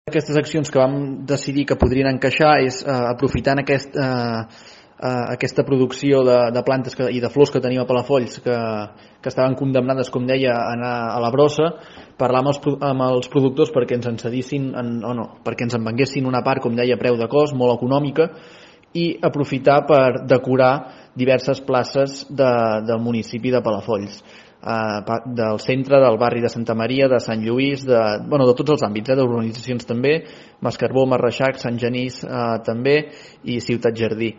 Aleix Freixa, regidor de Promoció Econòmica del consistori, explica que es van posar en contacte amb els negocis del sector que hi ha al nostre municipi per proposar-los la venda a preu de cost d’aquest excedent, per tal de mitigar l’impacte de la situació i poder donar sortida a les flors decorant amb flors les places de diferents indrets de Palafolls.